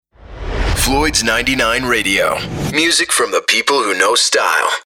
Branded Messaging
Floyds-Sweep-Station-ID-005.mp3